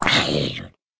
mob / zombie / hurt2.ogg
hurt2.ogg